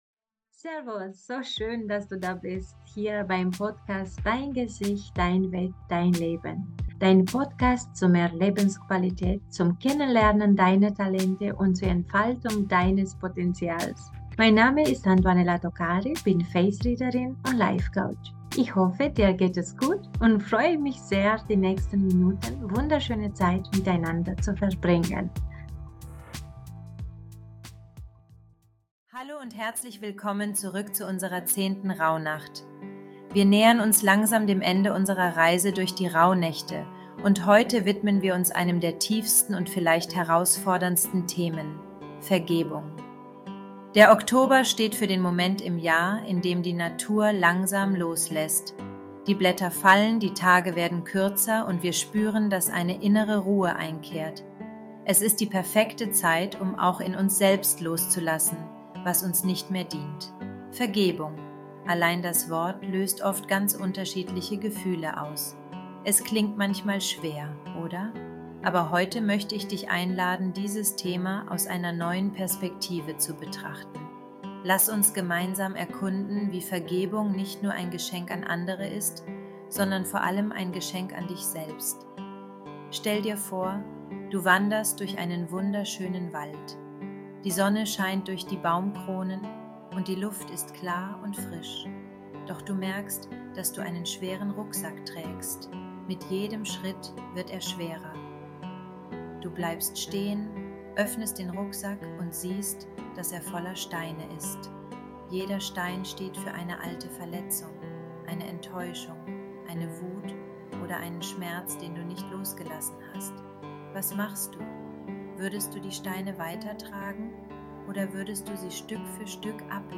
Eine Herzmeditation für mehr Frieden und Leichtigkeit